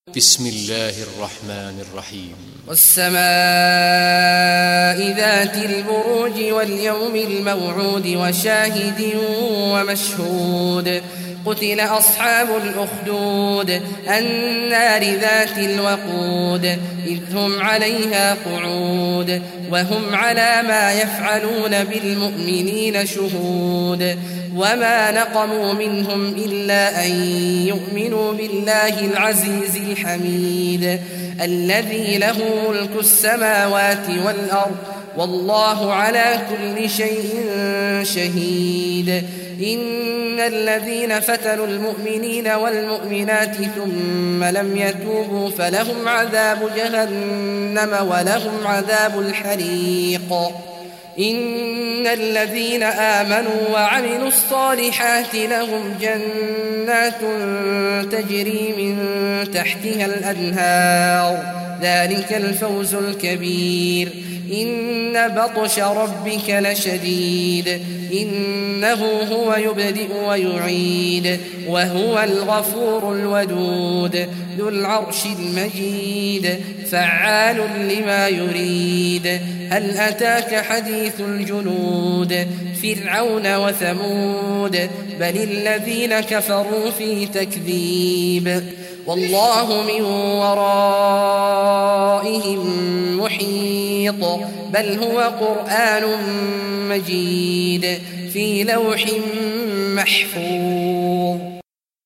Surah Buruj Recitation by Sheikh Abdullah Juhany
Surah Buruj, listen or play online mp3 tilawat / recitation in Arabic in the beautiful voice of Sheikh Abdullah Awad al Juhany.